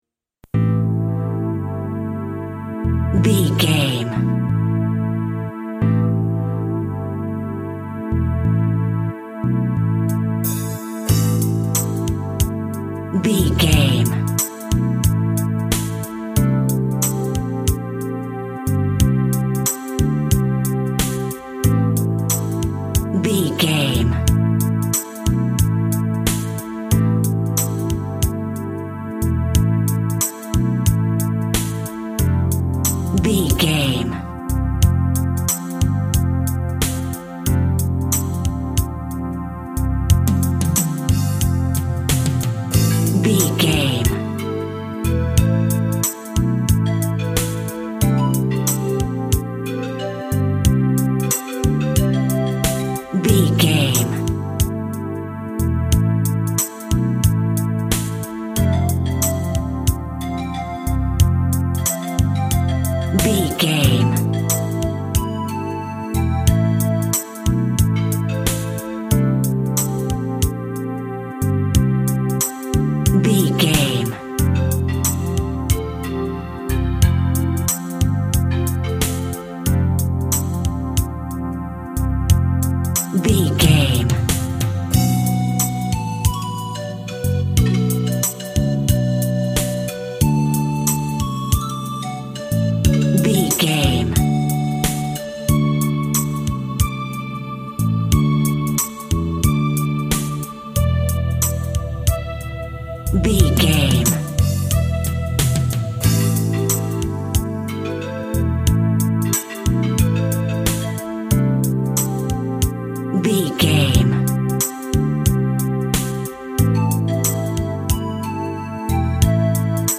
Eighties Synths.
Aeolian/Minor
groovy
calm
dreamy
synthesiser
drums
bass guitar
Retro
pop
electronic
synth bass
synth lead